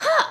damage1.wav